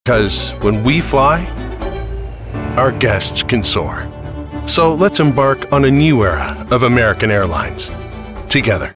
Voice Samples: American Airlines
male